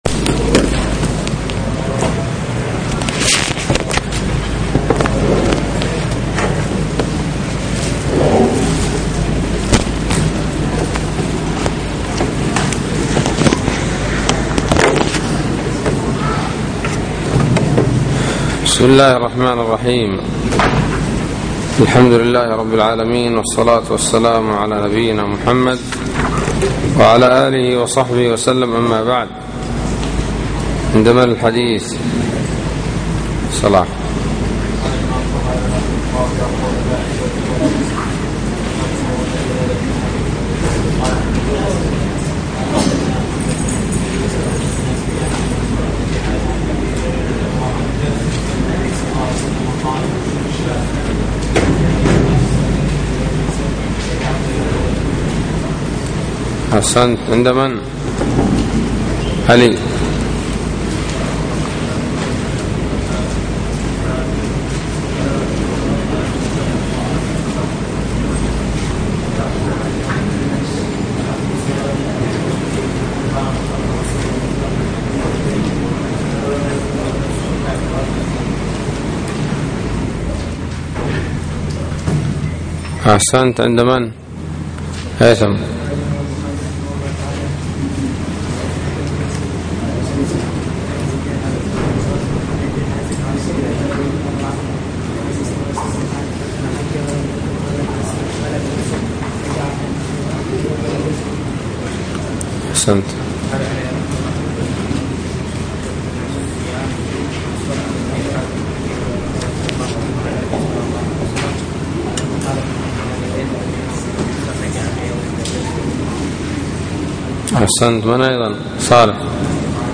الدرس الأول من كتاب جزاء الصيد من صحيح الإمام البخاري